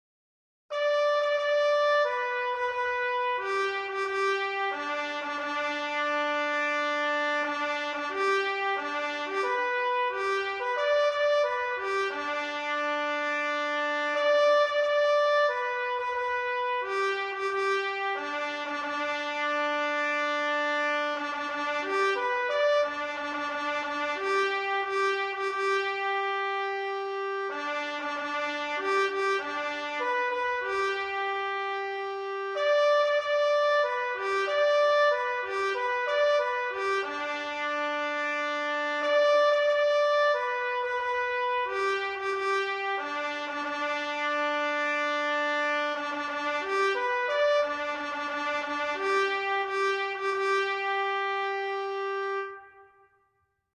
Traditional : Retreat or Sunset - a military bugle call: Sheet Music
Traditional Bugle Call : Retreat (or Sunset)